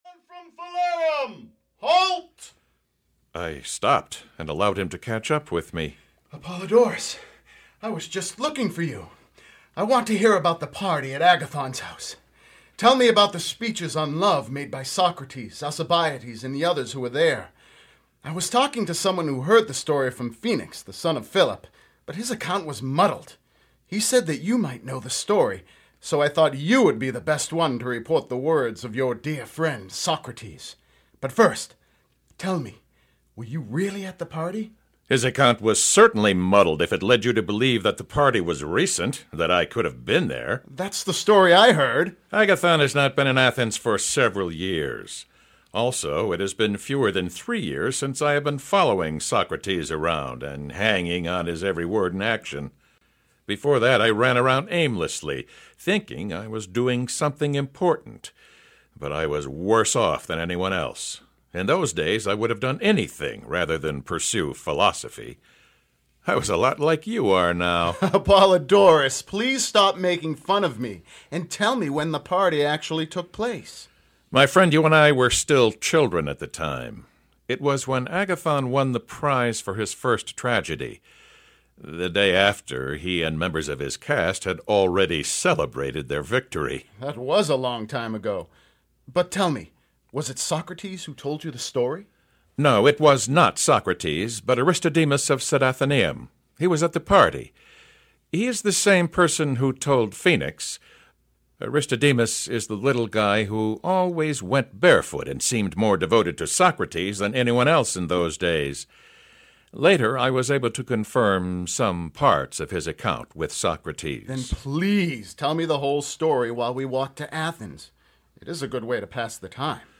Plato’s Symposium (EN) audiokniha
Ukázka z knihy